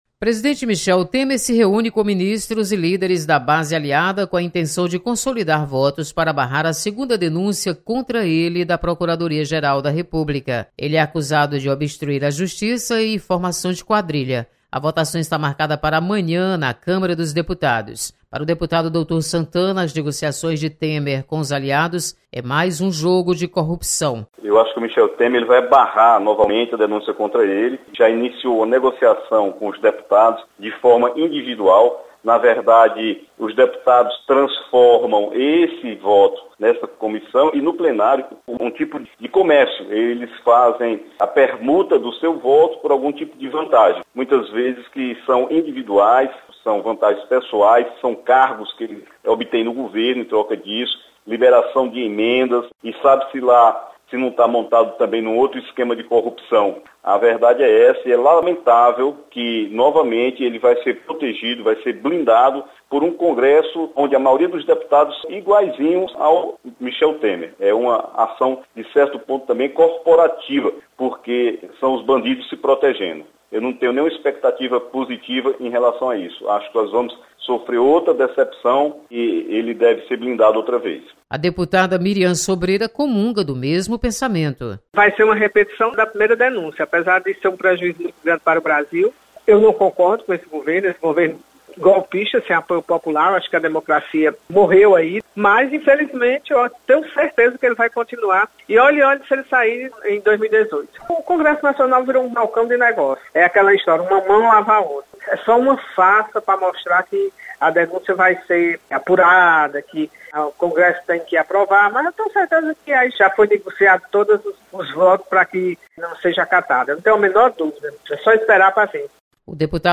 Deputados estão descrentes da punição de Michel Temer. Repórter